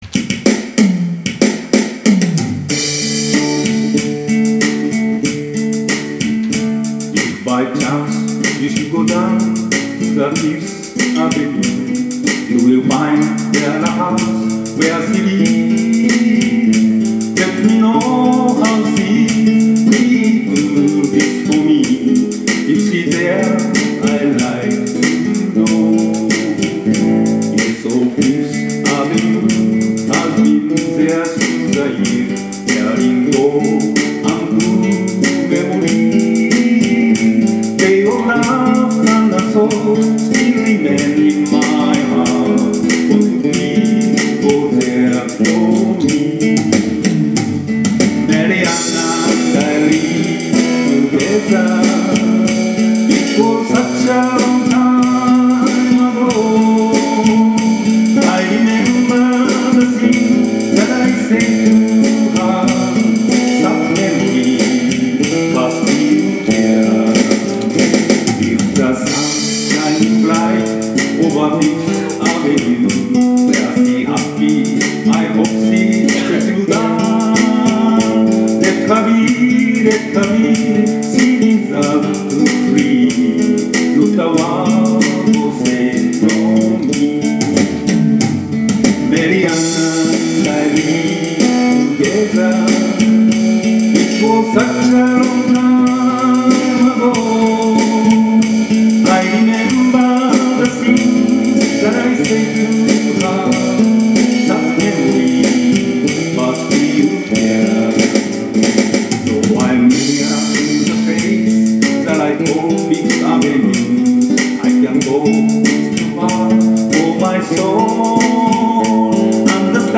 ジャズ系のギター弾き語りが得意で、最近ではストリートライブ活動もしているそうです。
弾き語り